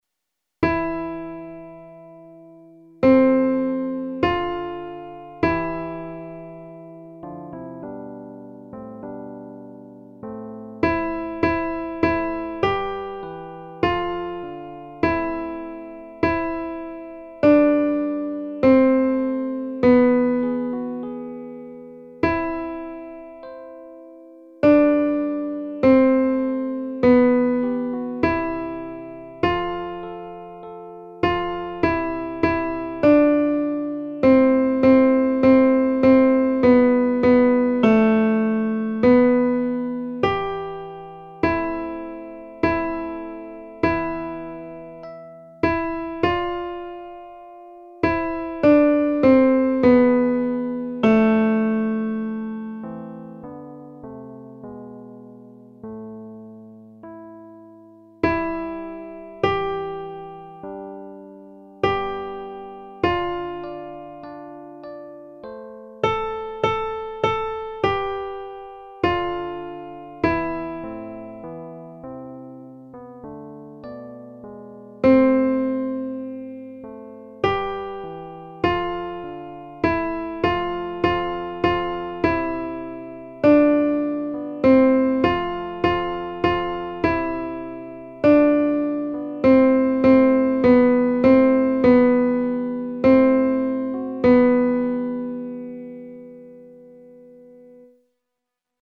Audios para estudio (MP3)
Alto